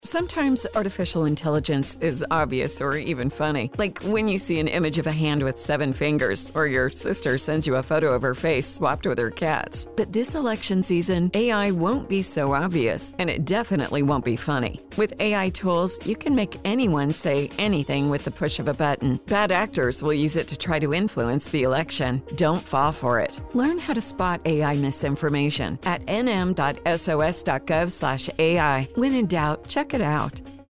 Seeing Is No Longer Believing Radio Ad - English
SOS-30SEC-AI-Radio-Ad_English.mp3